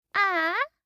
알림음 8_BoyAh4.mp3